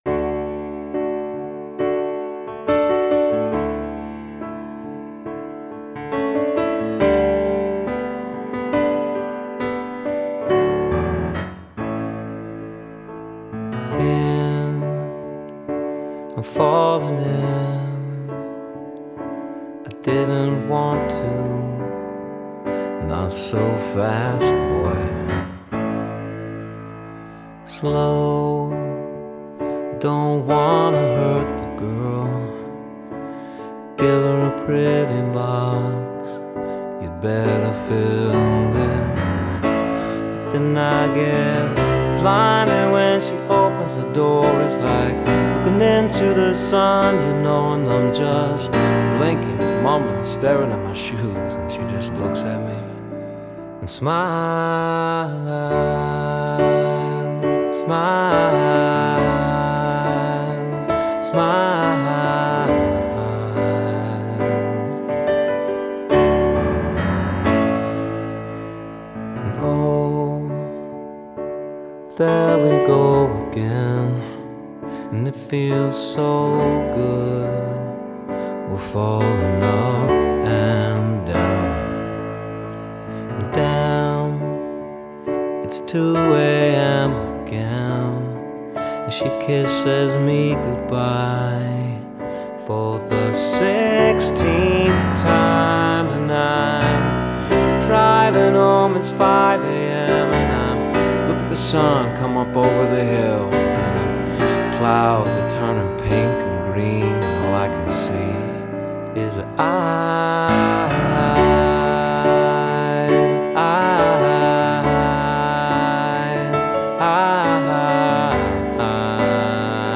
Bluesy-rock is the best way to describe it.
beautiful song on piano